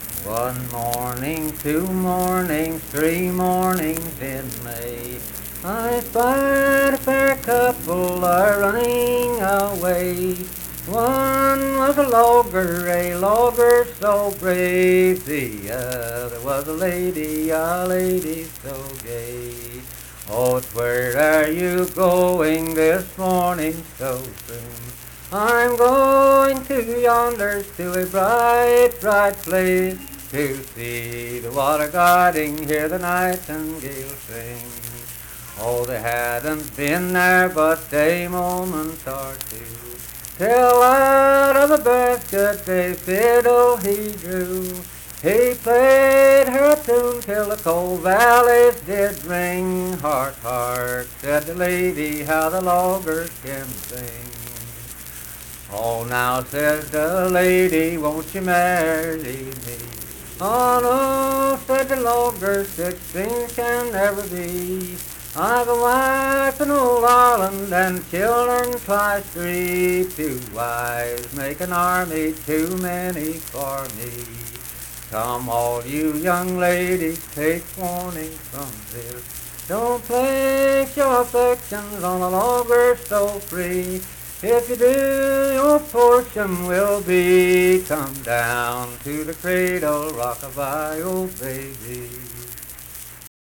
Unaccompanied vocal music performance
Performed in Kliny, Pendleton County, WV.
Voice (sung)